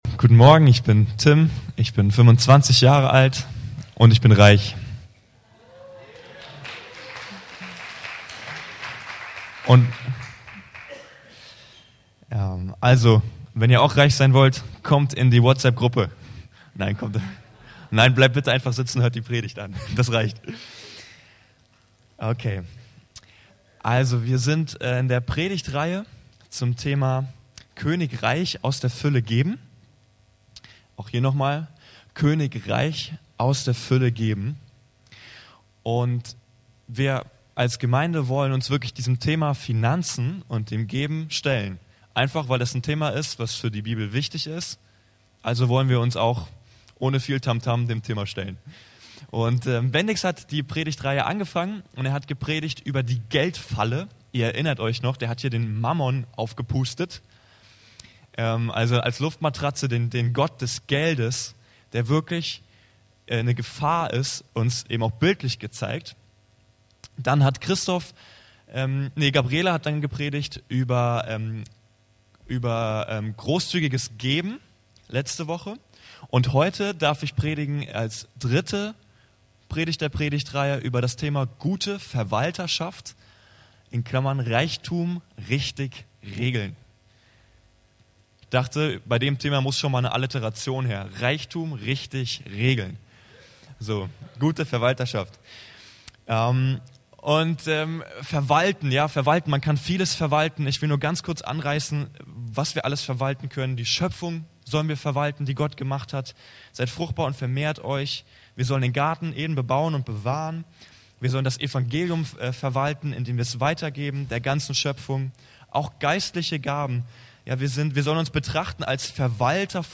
Anskar-Kirche Hamburg- Predigten Podcast